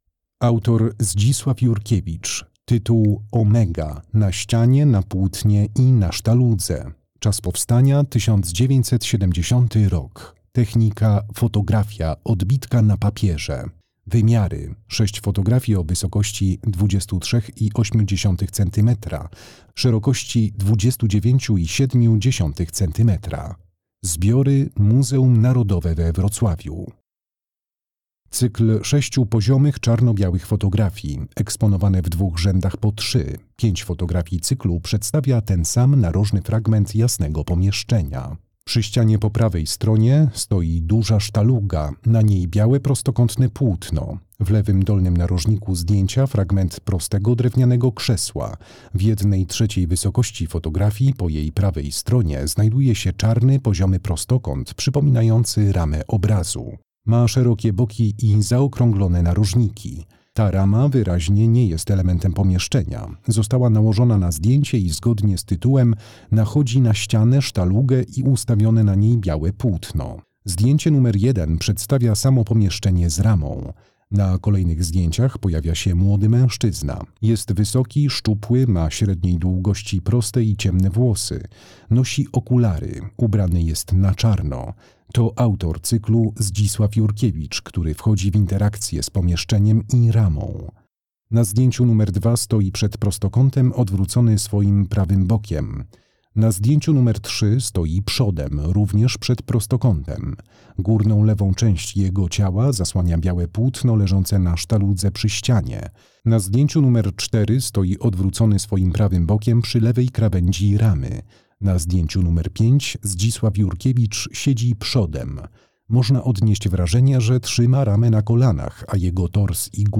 Audiodeskrypcje